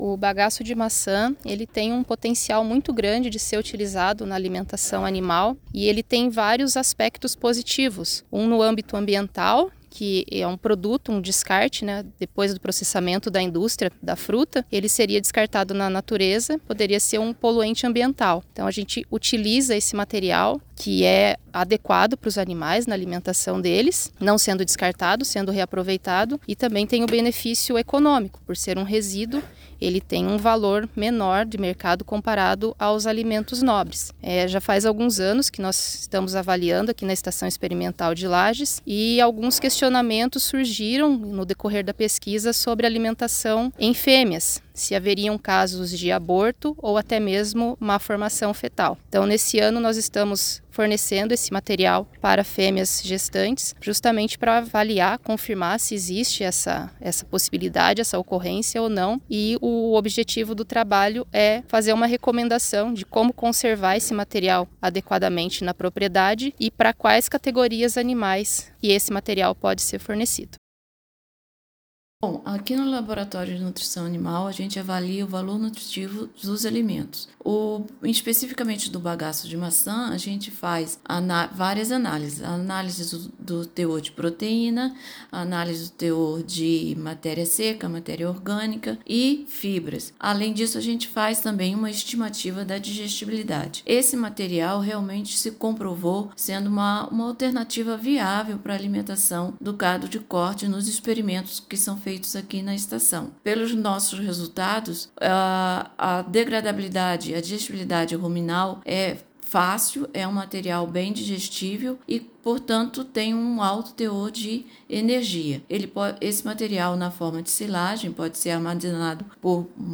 SONORA – Epagri de Lages estuda o uso do bagaço da maçã na pecuária de corte